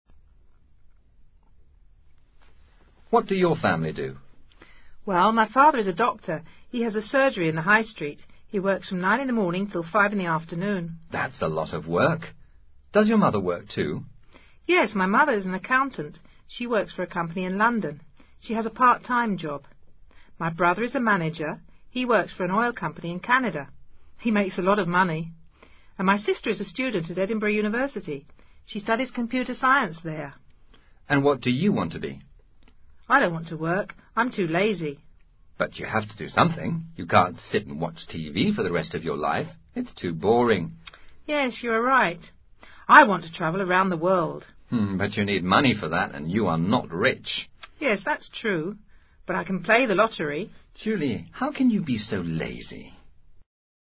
Diálogo sobre la familia y las ocupaciones de las personas.